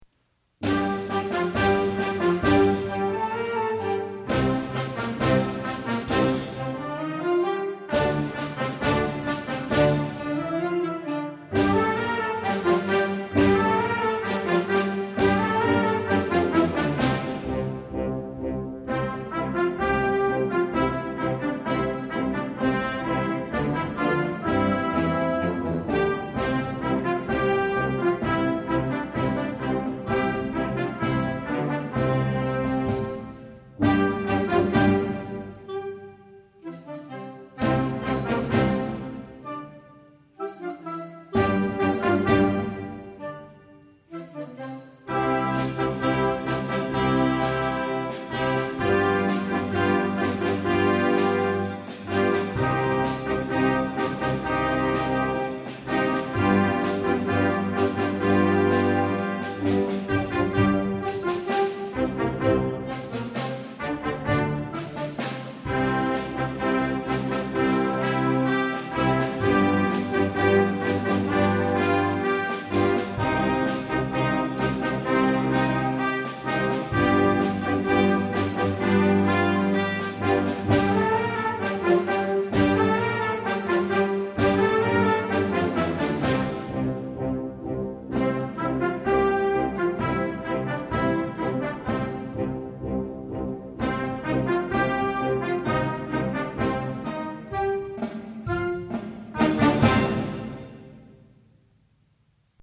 Genre: Band
Flute
Oboe
Alto Saxophone
Tenor Saxophone
Baritone Saxophone
Trumpet
F Horn
Trombone
Tuba
Percussion 1 (snare drum, bass drum)
Percussion 2 (suspended cymbal, tambourine, triangle)